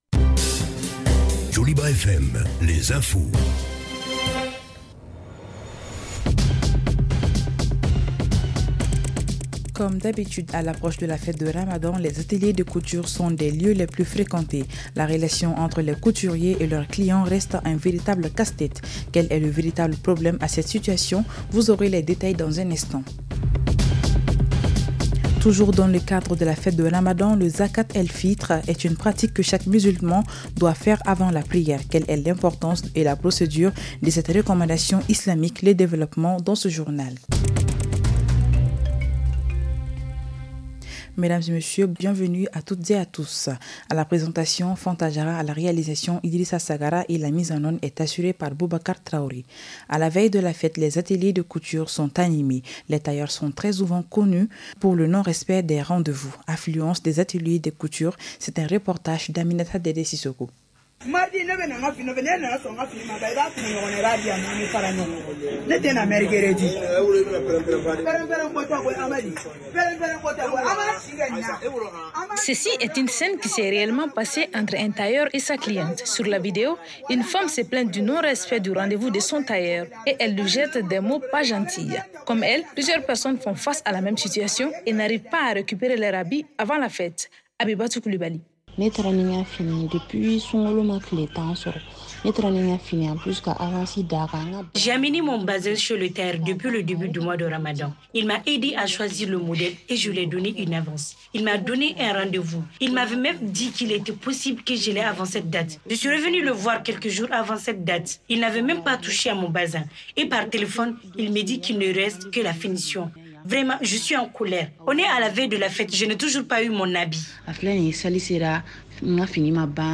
REPLAY 20/04 – Le journal en français de 17h30